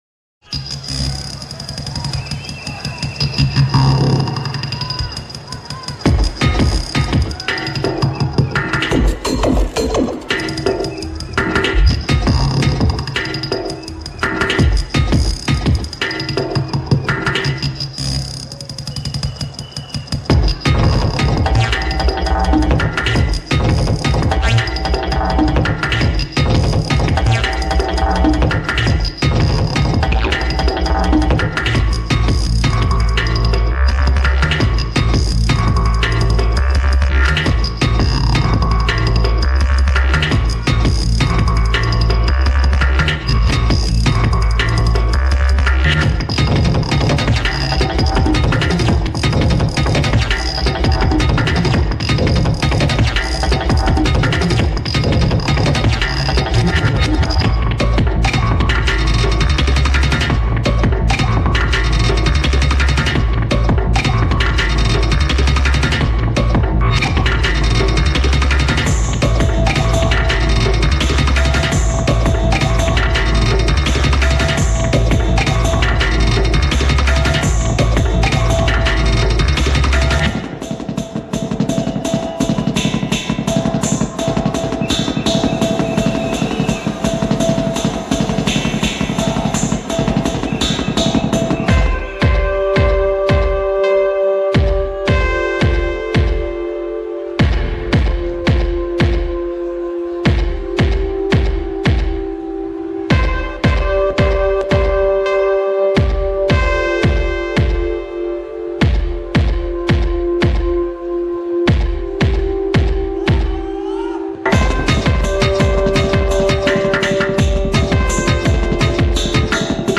venue Worthy Farm event Glastonbury